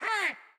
MB Vox (5).wav